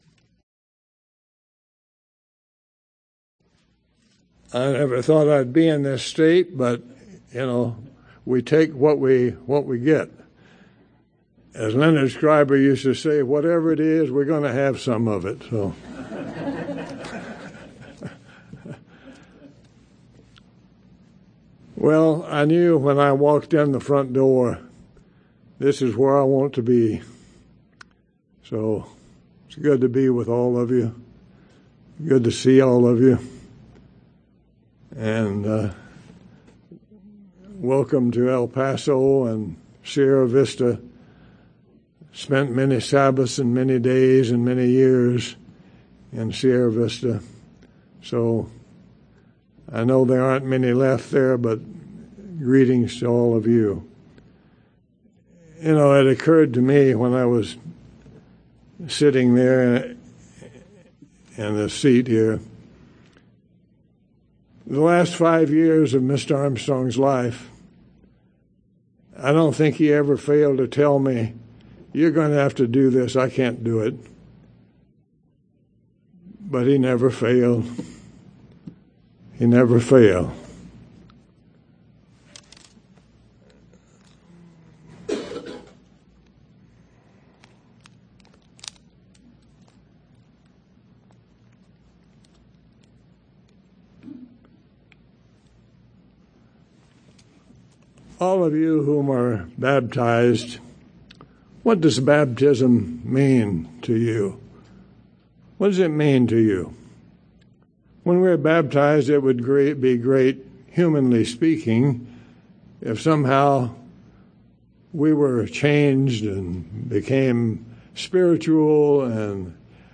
However, there are many baptisms of life during our brief stay in this mortal life. We shall examine a few of them in this sermon.